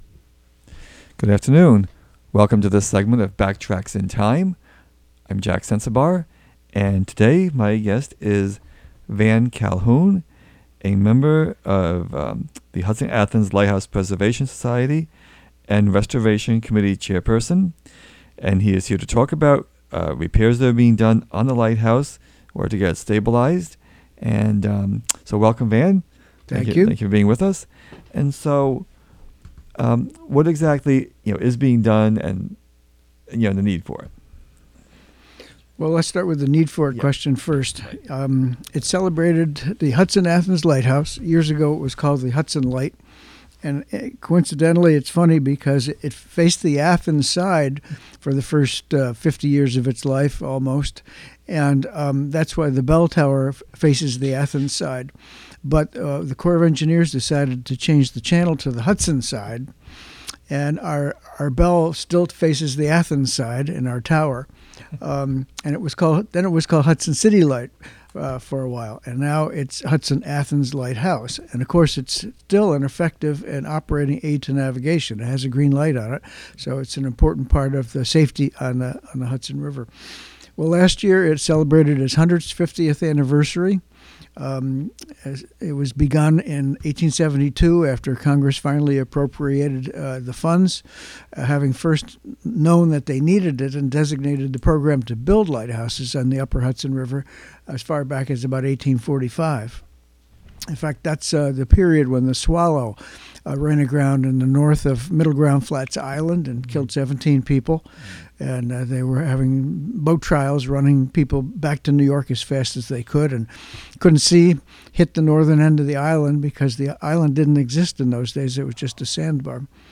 Dedicated to the history of Greene County, its notable people and places and the Hudson River. The show features interviews with local historians, longtime residents with stories to tell, and archival recorded material.